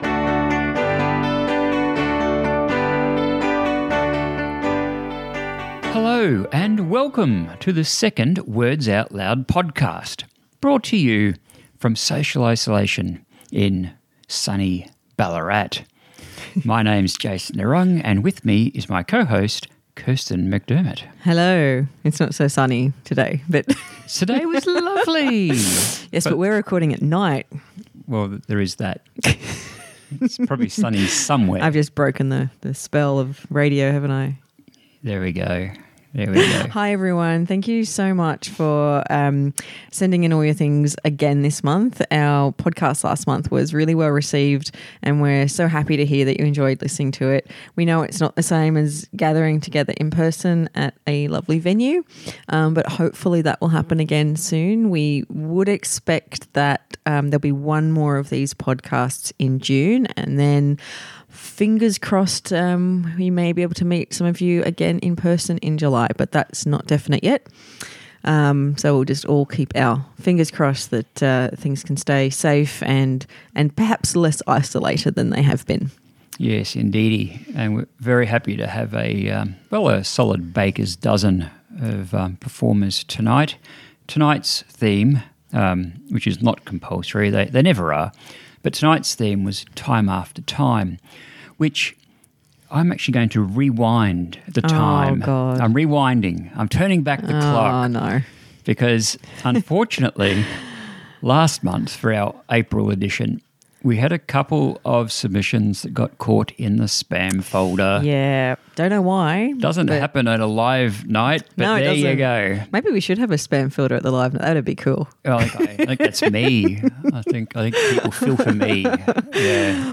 All up, we have 13 artists presenting work, and we greatly appreciate those who contributed using whatever recording device was at hand to get these to us.